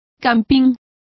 Complete with pronunciation of the translation of campground.